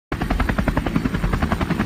helicopter flying sound Meme Sound Effect
helicopter flying sound.mp3